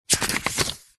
Звуки паспорта
Звук печати паспорта в додатку